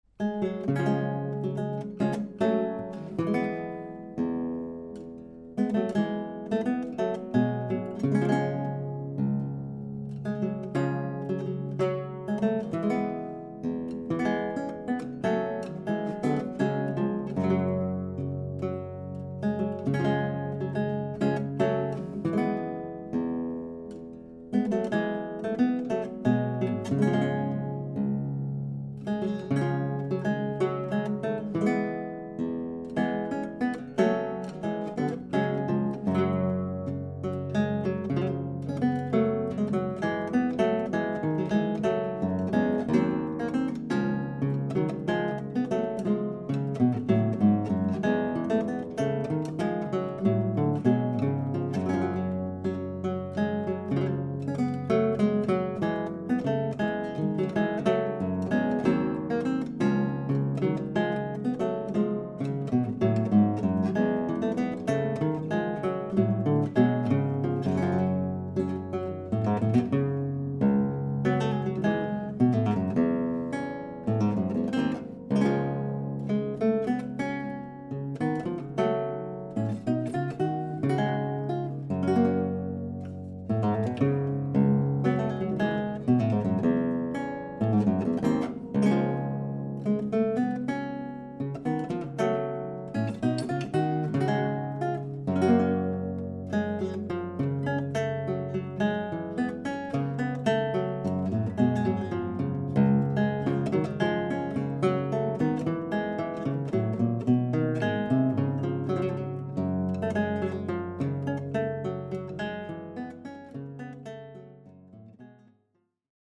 Auf Anfrage vom Tontechnikerforum 3db hier ein paar Samples, die die anerkannte Kombination CMC5 + MK2s von Schoeps mit dem vergleichsweise sehr günstigen Oktava MC012 mit Kugelkapsel vergleichen.
RME Fireface 800, Wohnzimmer, Abstand: ca. 0,5m, AB-Stereo mit Basis 30cm, keine Effekte oder EQ.
Romanesca1MC012omni.mp3